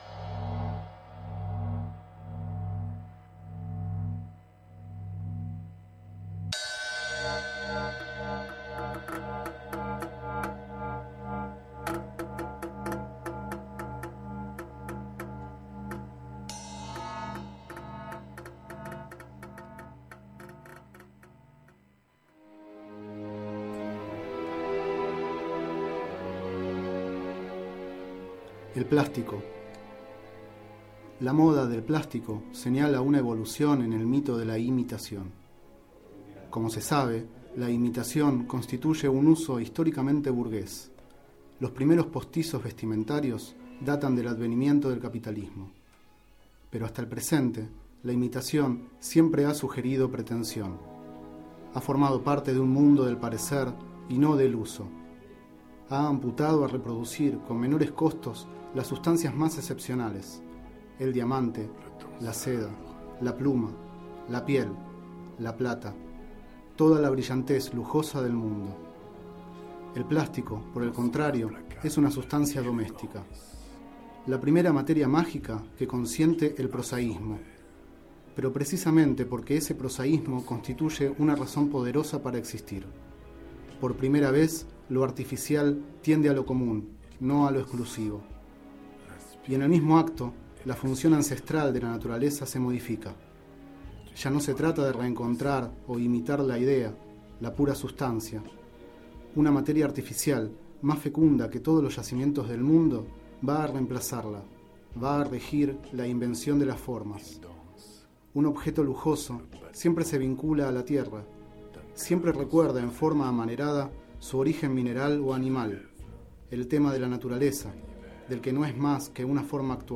Este es el 11º micro radial, emitido en el programa Enredados, de la Red de Cultura de Boedo, por FMBoedo, realizado el 30 de noviembre de 2010, sobre el libro Mitologías, de Roland Barthes.
El siguiente es el fragmento leído del libro El sistema de la moda (1967):
Durante el micro se escuchan los temas Le danseur de Arthur H. (2006) y Rock around the bunker de Serge Gainsbour (1975)